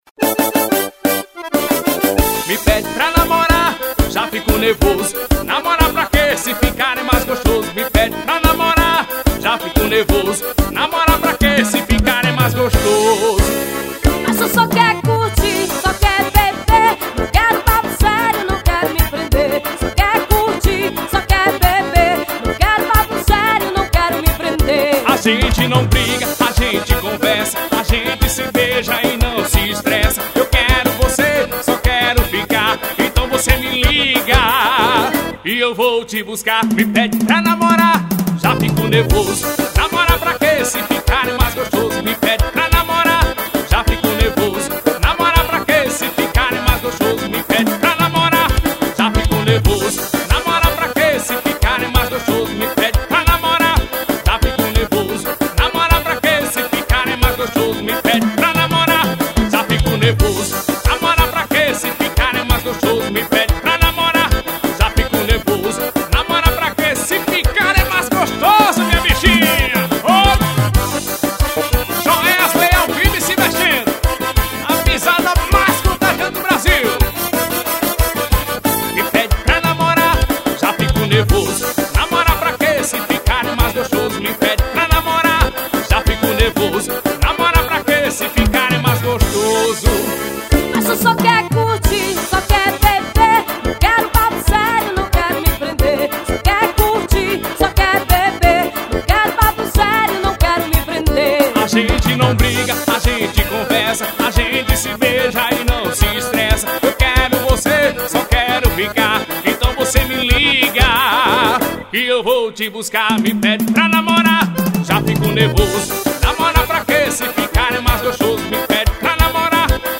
ao vivo e se mexendo